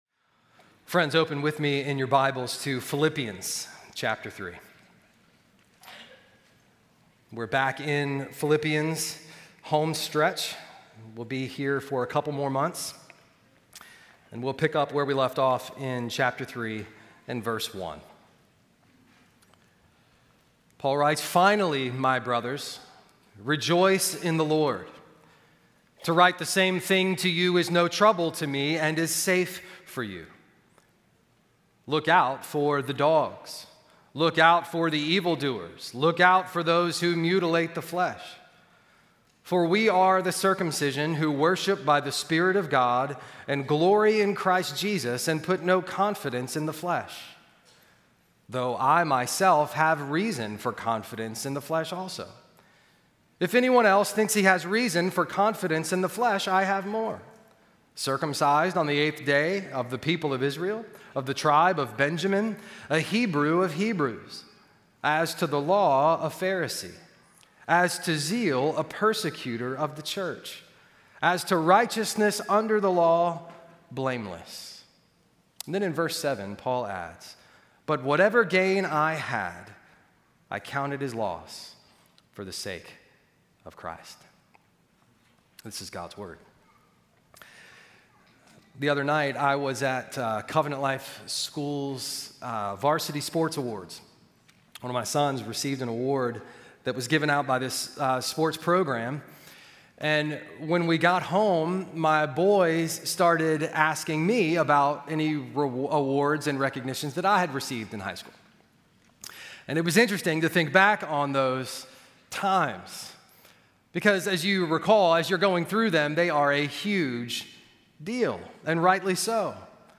The HeavenEarth Church Podcast Exists to Amplify The Conversation Happening at HeavenEarth Church. This conversation centers around the vision to be a church that make a lasting impact in our community by building relationships with all kinds of folks, helping people know and live like Jesus together.